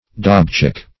dobchick - definition of dobchick - synonyms, pronunciation, spelling from Free Dictionary
Dobchick \Dob"chick`\, n. (Zool.)